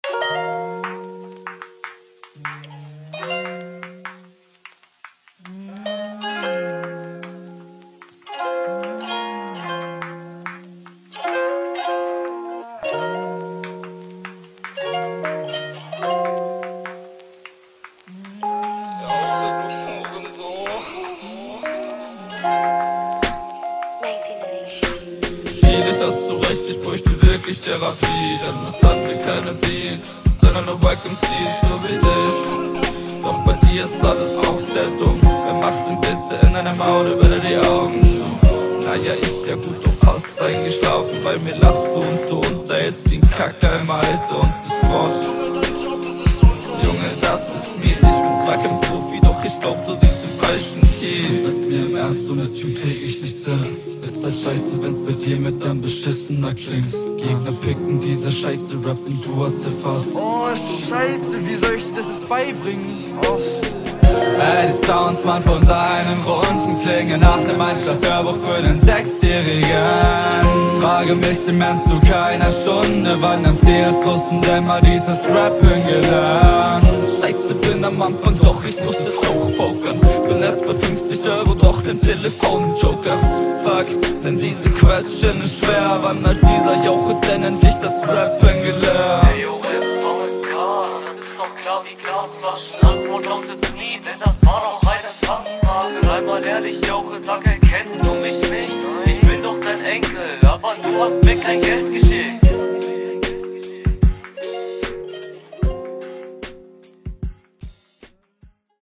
Leider echt unverständlich. Doubles sehr scheiße gemischt.